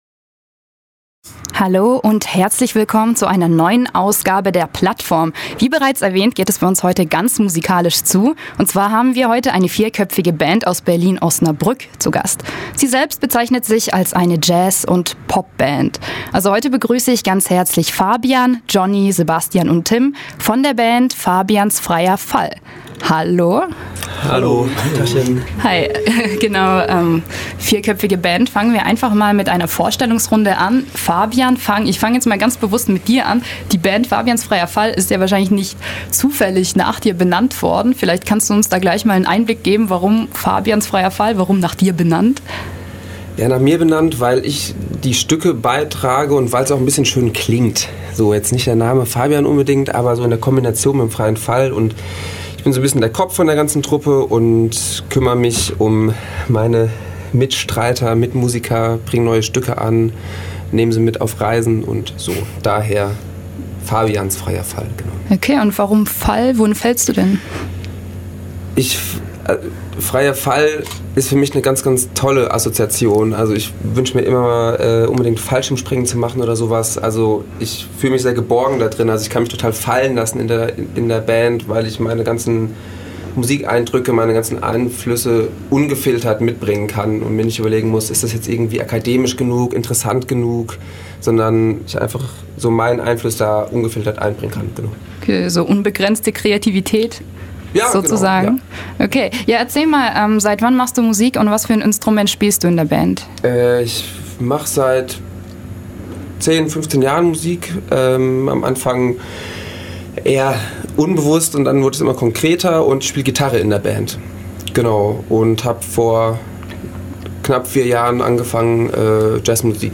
Die Band "Fabians Freier Fall" war heute zu Gast in der Plattform. Musikalisch bewegt sich das Quartett zwischen Ibrahim Maalouf, Bill Frisell, Radiohead und U2 - eine Mischung aus Jazz und Pop.